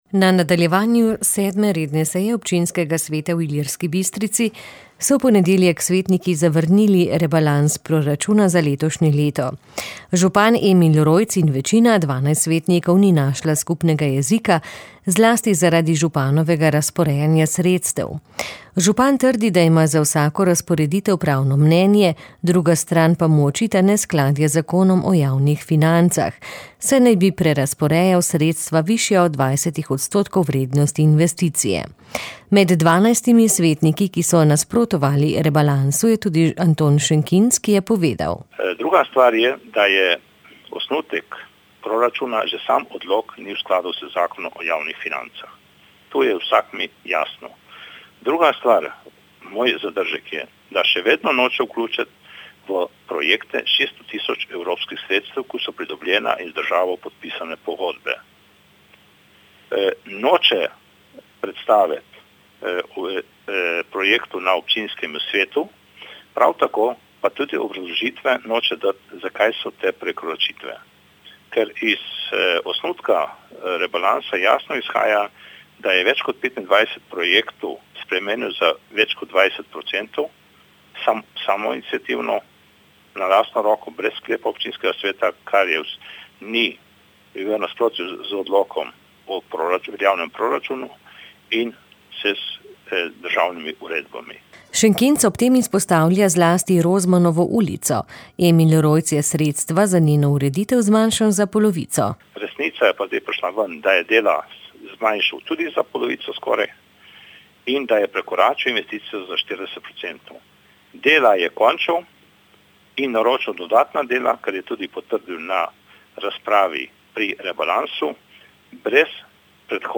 Župan je o posledicah, ki jih bo sprožilo nestrinjanje občinskega sveta, novinarje opozoril v izjavi za javnost.